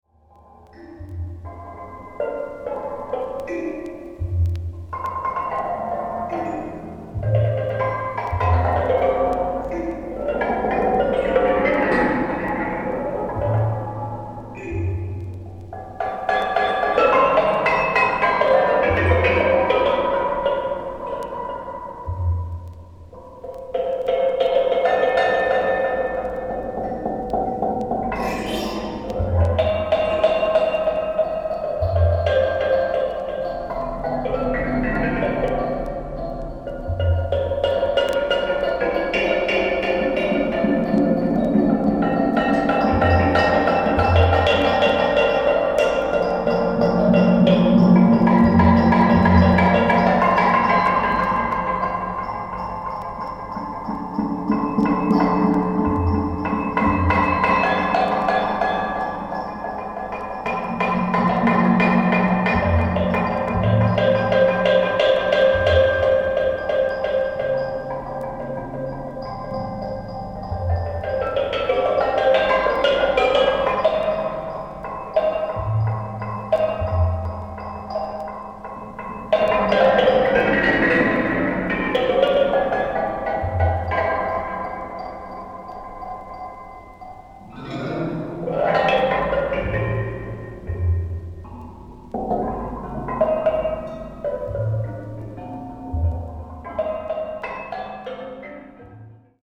キーワード：ミニマル　創作楽器　空想民俗　Structures Sonores　即興